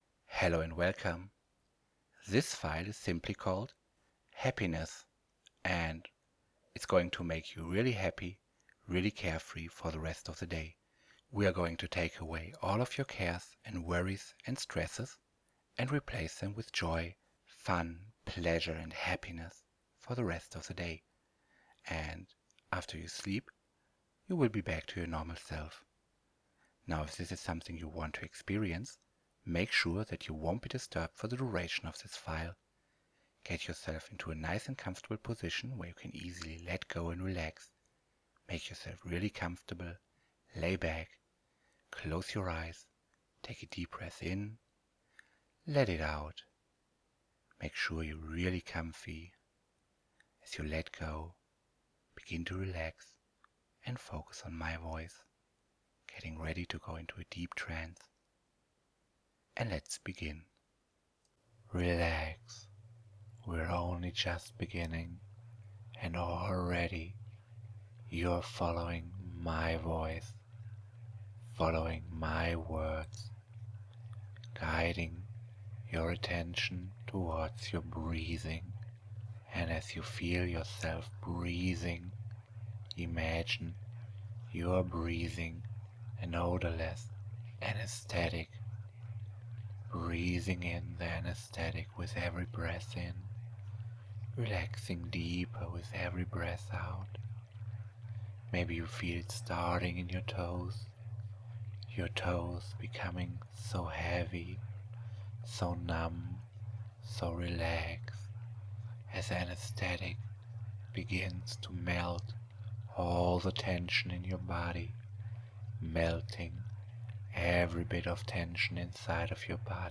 Happyness will flood your mind with this free hypnosis recording